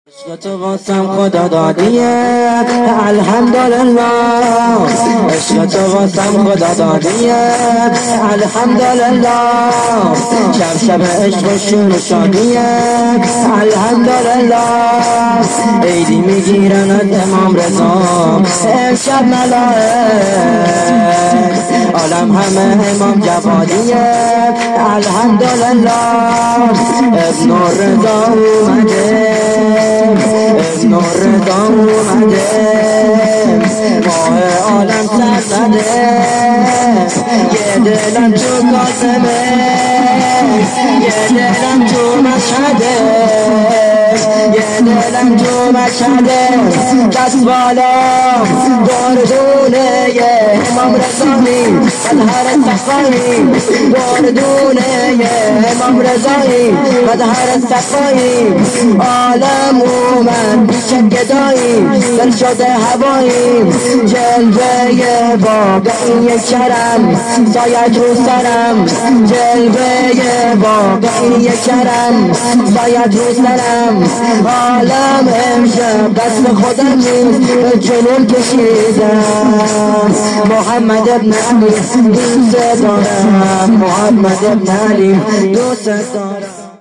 جشن ها